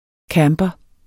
Udtale [ ˈkæːmbʌ ]